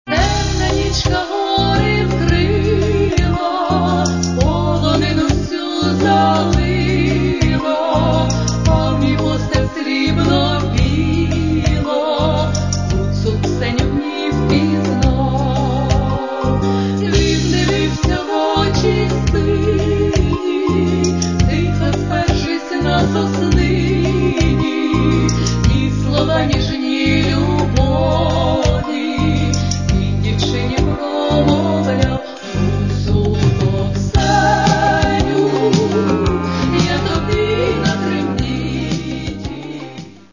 Каталог -> Естрада -> Дуети